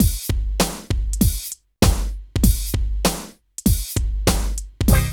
74 DRUM LP-R.wav